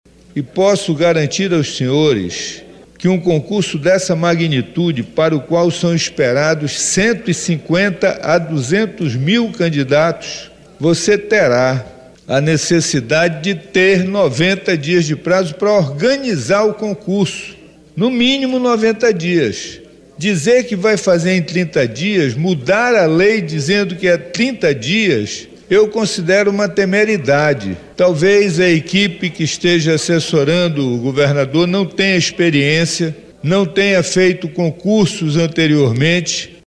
Atualmente, o prazo para a primeira prova ser aplicada é de 90 dias, mas o Governo quer reduzir para 30 dias após a publicação do edital. O deputado Serafim Correa defende a manutenção dos três meses.